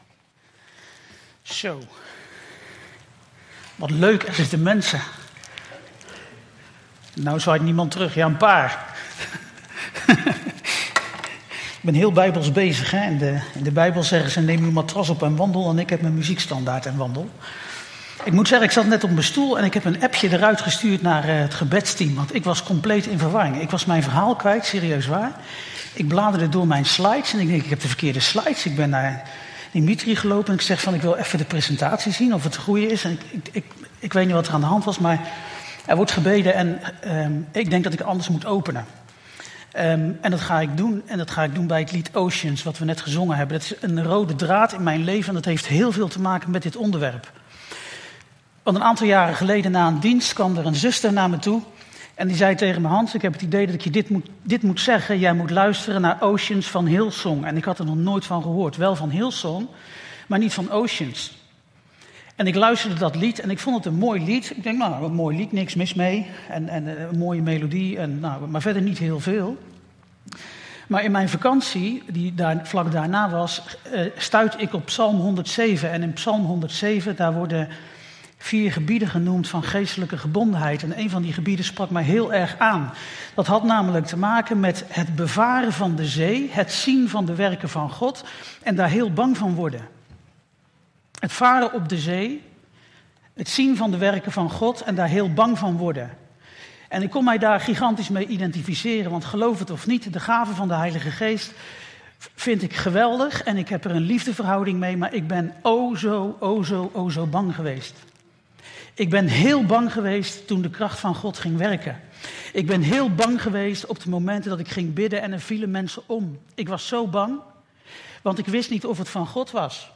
Toespraak 6 juni: de Heilige Geest - De Bron Eindhoven